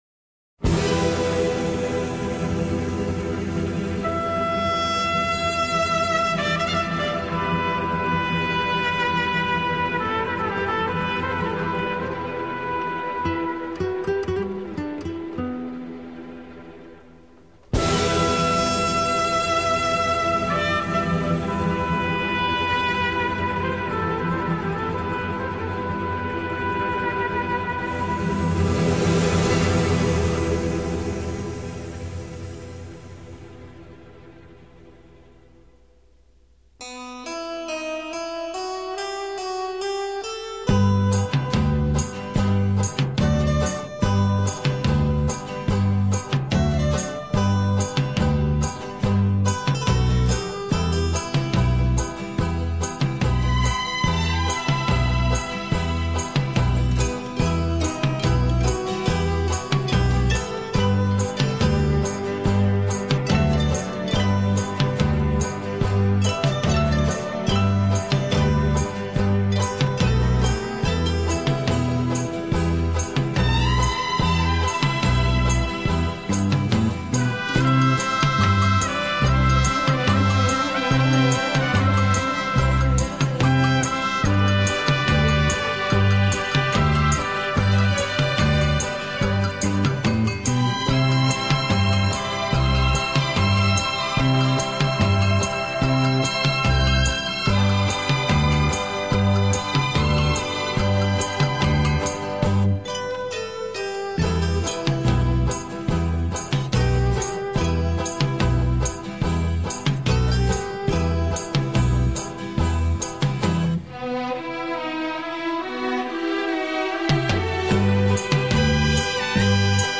人类音乐史上最优美的轻音乐全集 大师示范级经典作品